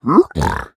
Minecraft Version Minecraft Version 25w18a Latest Release | Latest Snapshot 25w18a / assets / minecraft / sounds / mob / piglin / admire1.ogg Compare With Compare With Latest Release | Latest Snapshot
admire1.ogg